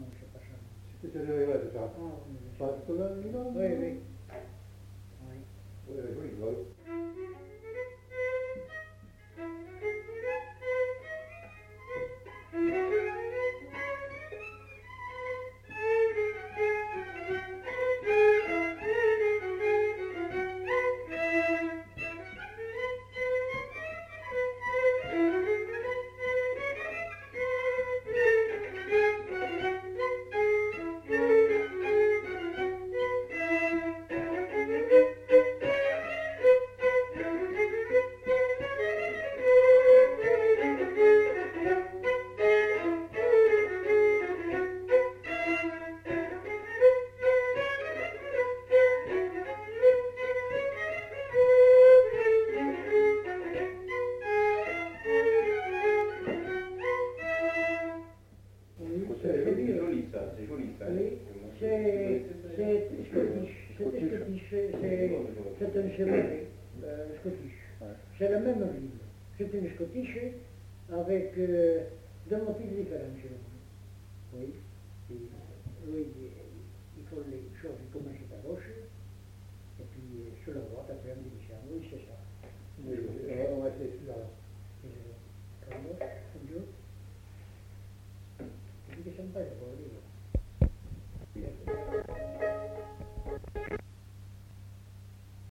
Aire culturelle : Lomagne
Département : Gers
Genre : morceau instrumental
Instrument de musique : violon
Danse : scottish